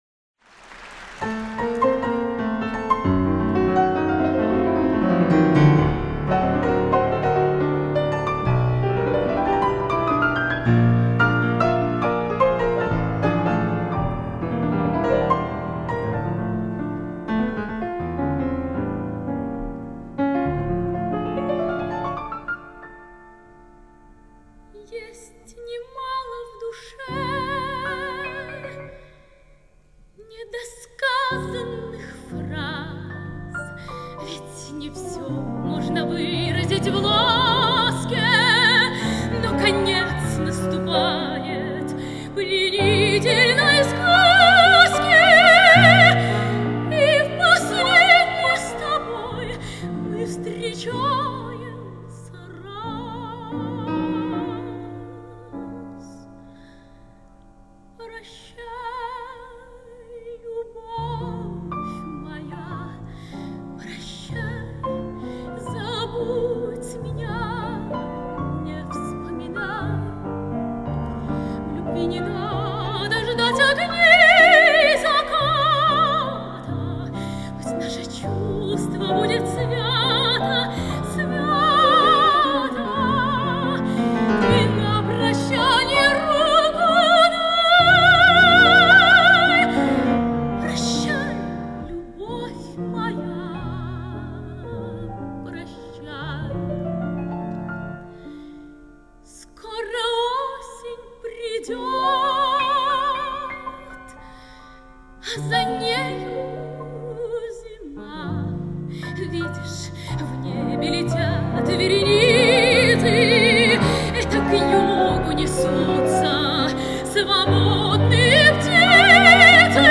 сопрано